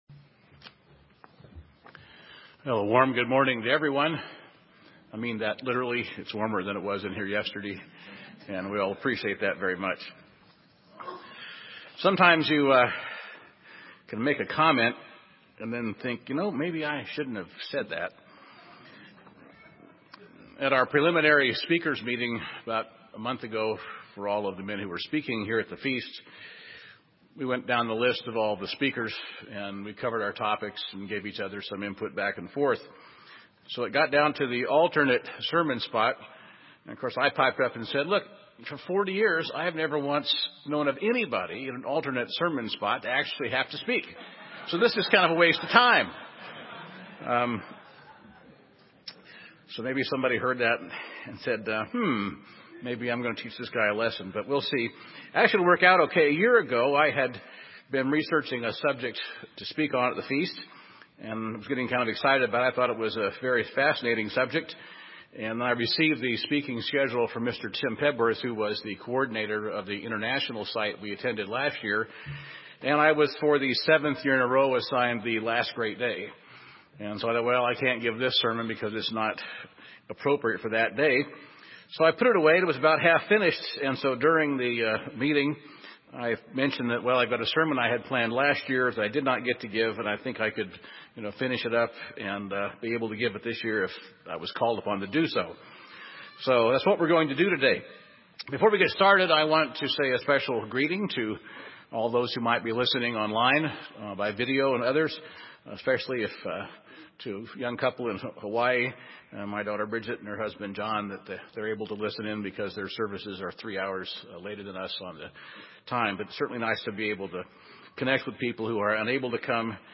This sermon was given at the Bend, Oregon 2014 Feast site.